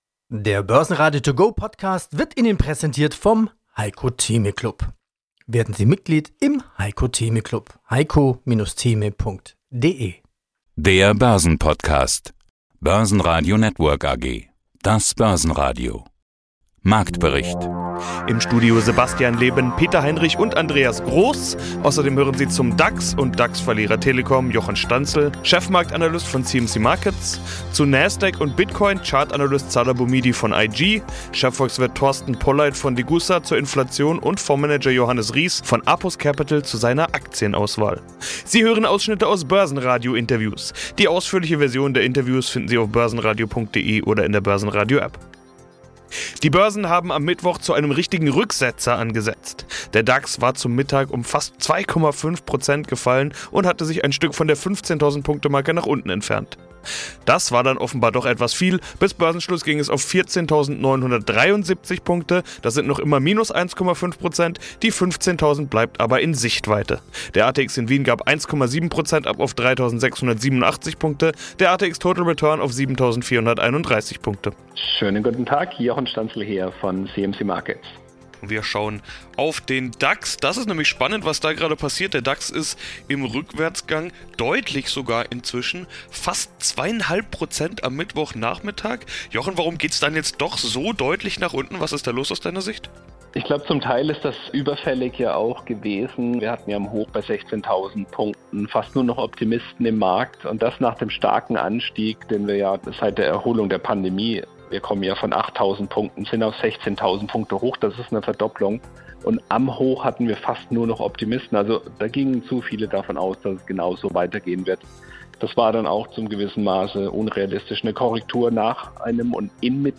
Sie hören Ausschnitte aus Börsenradiointerviews.